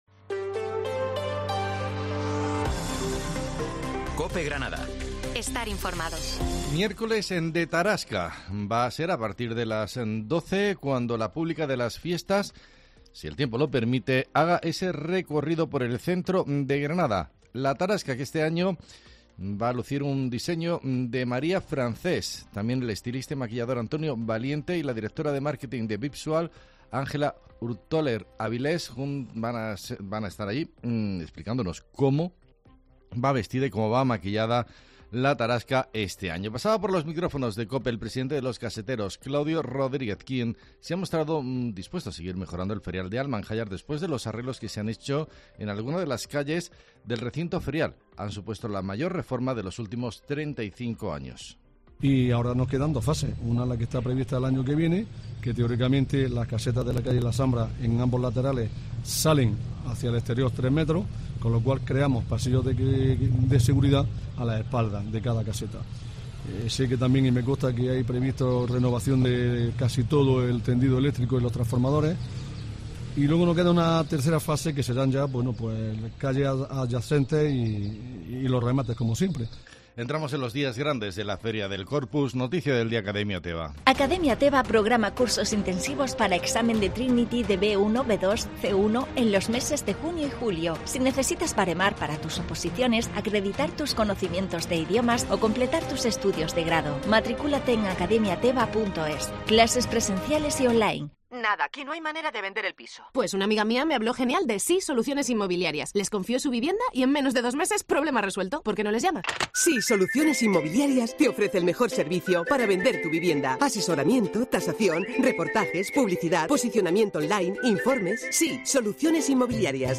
Herrera en COPE Ganada, Informativo del 7 de junio